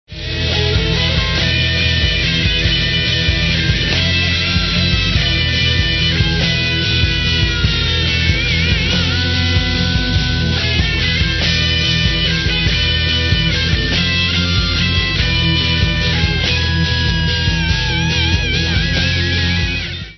rock
stereo